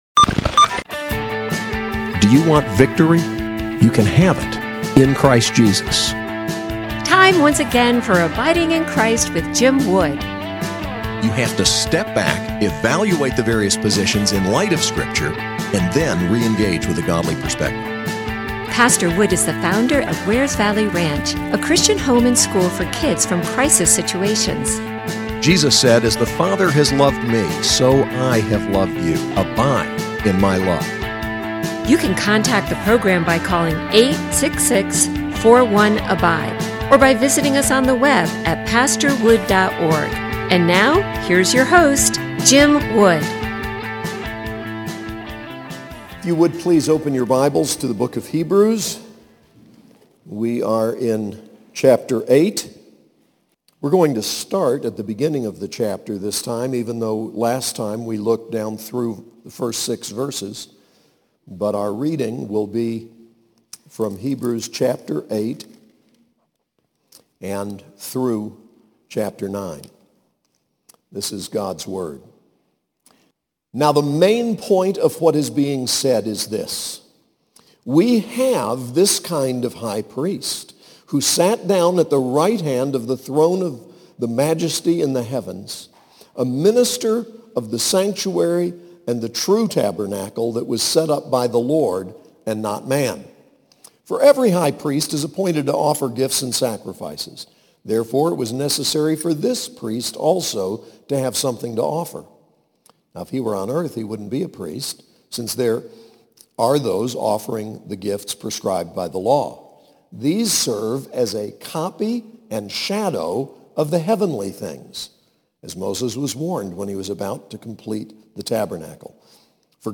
SAS Chapel: Hebrews 8 and 9